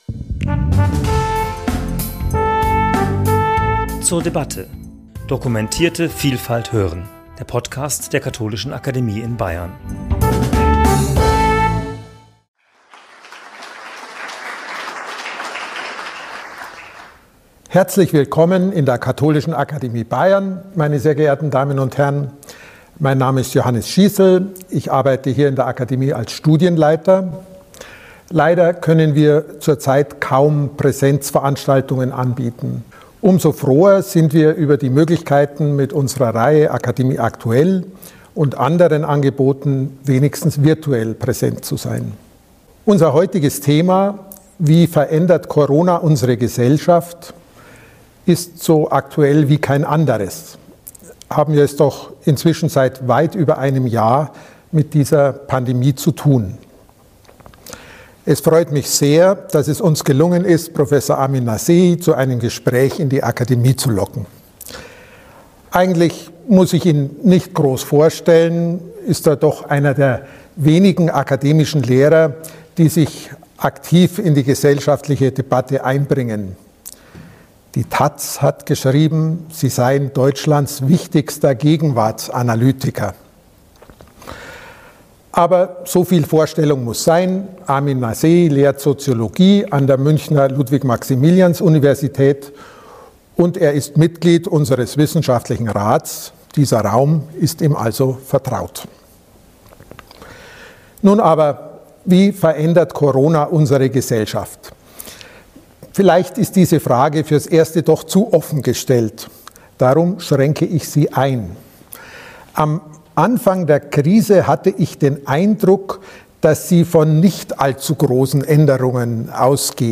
Armin Nassehi, der auch zahlreiche Politiker berät, gab zudem Auskunft zur Rolle der Medien in der Pandemie. Weiterhin kam die Situation der Wissenschaft und bei den Studierenden zum Tragen; abgerundet wurde das etwa 45-minütige Interview durch die Themenbereiche Kultur und Kirche.